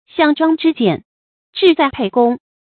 注音：ㄒㄧㄤˋ ㄓㄨㄤ ㄓㄧ ㄐㄧㄢˋ ，ㄓㄧˋ ㄗㄞˋ ㄆㄟˋ ㄍㄨㄙ
讀音讀法：
項莊之劍，志在沛公的讀法